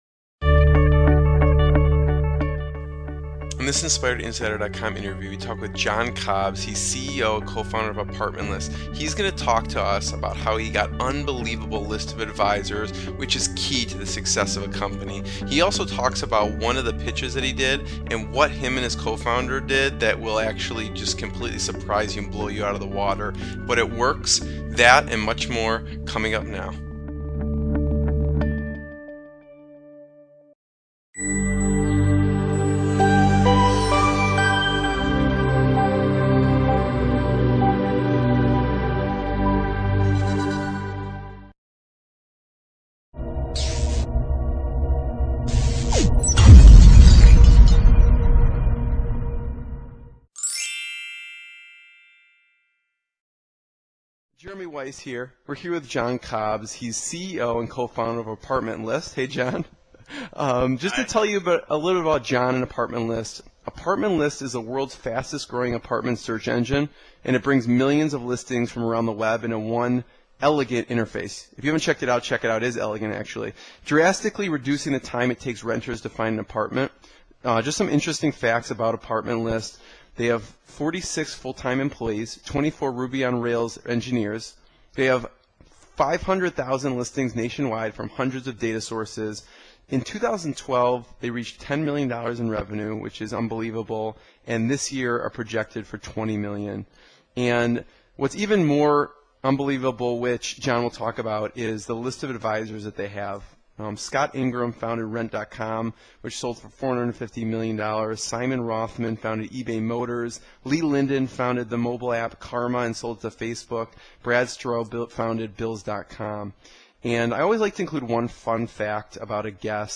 In August 2013, Inc. Magazine named Apartment List as the #108th fastest growing company in America What will you learn in this interview?